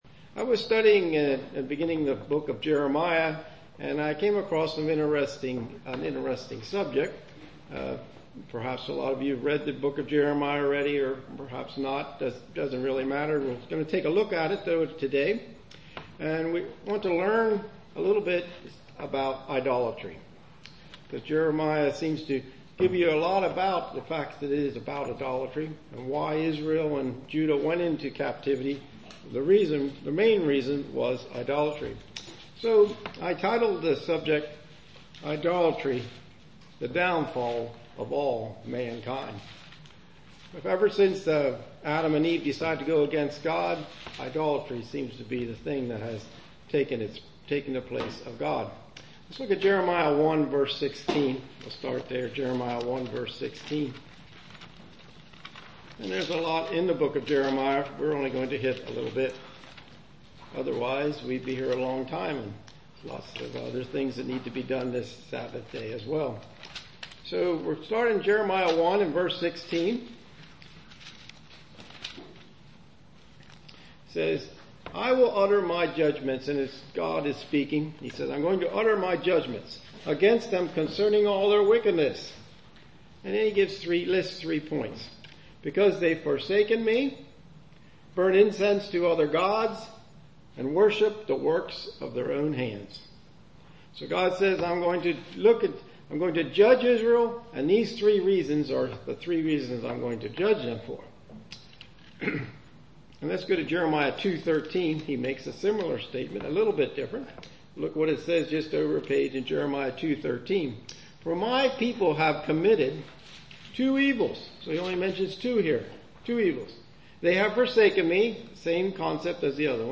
Sermons
Given in Lewistown, PA York, PA